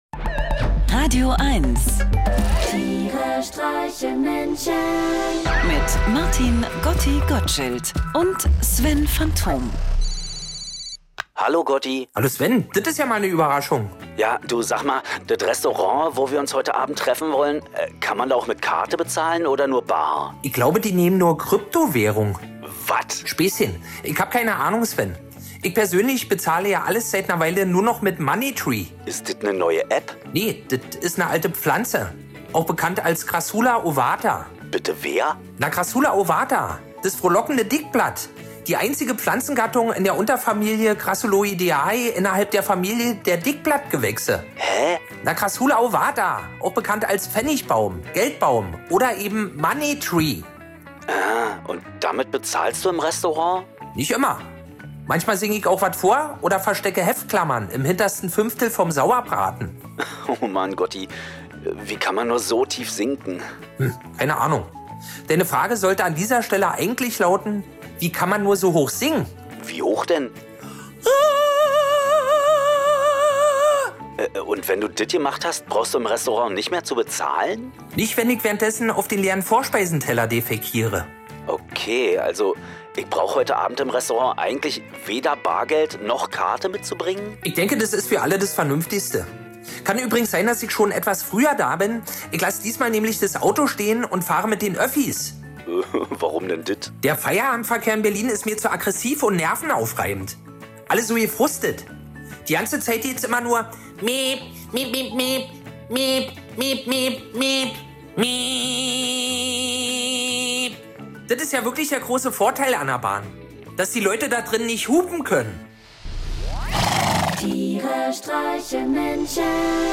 Einer liest, einer singt und dabei entstehen absurde, urkomische, aber auch melancholische Momente.